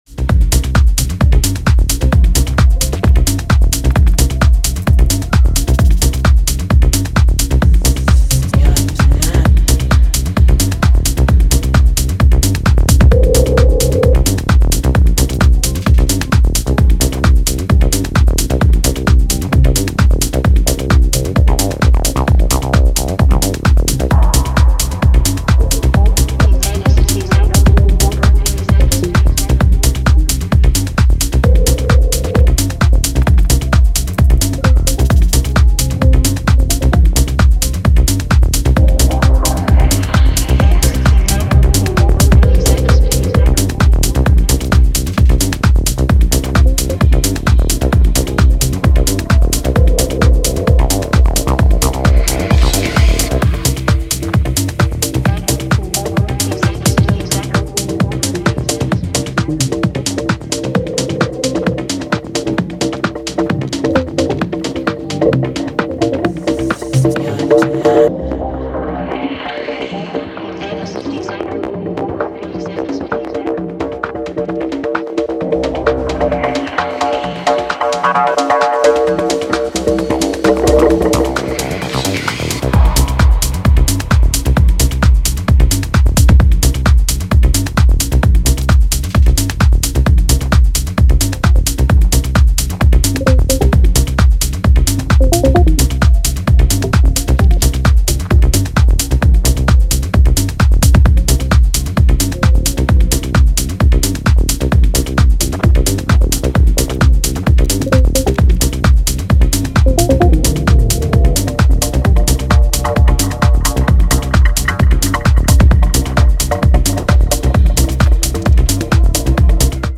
図太いベースラインとシャープなコンガロールで思わずテンションが上がるファンキーアシッド